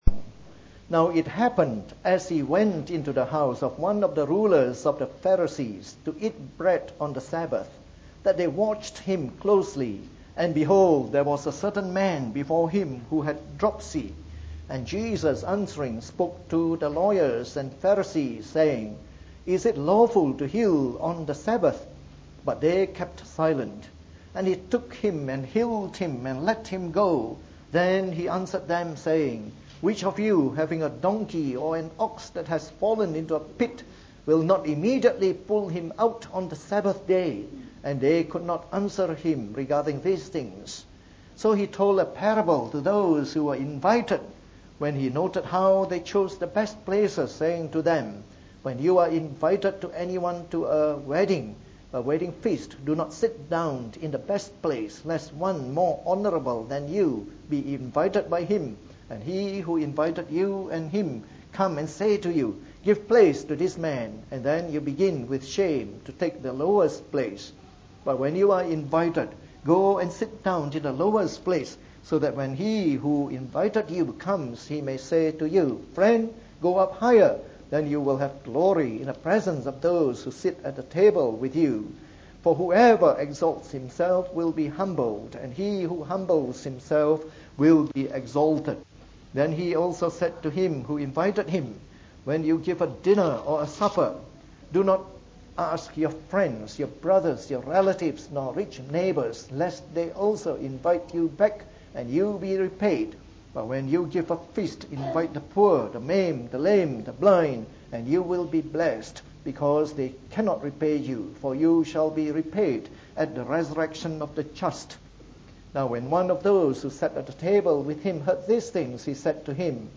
Preached on the 23rd of March 2014. From our series on the “Gospel According to Luke” delivered in the Evening Service.